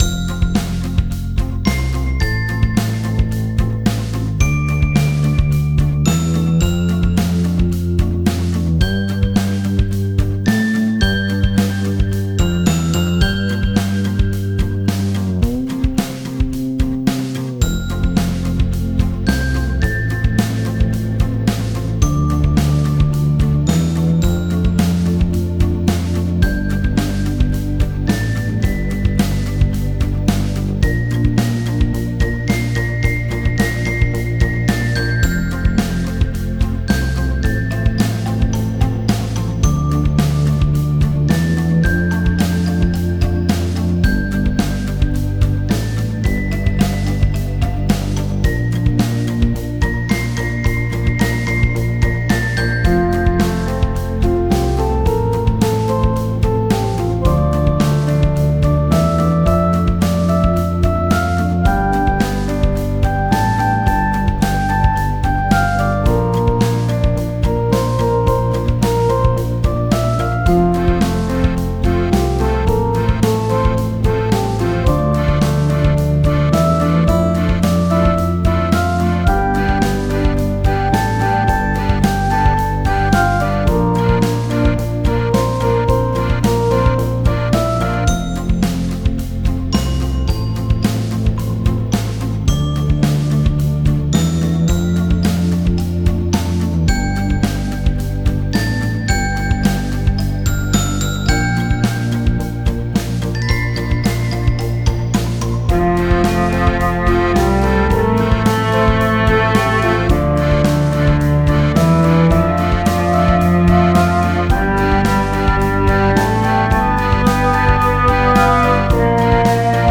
かわいらしく温かいファンタジー曲 です。
軽やかなリズムに柔らかい音色が重なり、アニメ・ゲームの村エリアや、のんびりした日常シーンにぴったり。
• テンポはゆったり、揺らぎのあるリズム感
• 高音域の軽いベル系サウンド
• 温かいパッドとアコースティック系の音色
※ループ処理済みですぐ使えます。
フリーBGM ほのぼの アンビエント ファンタジー 癒し 自然 可愛い 小人 日常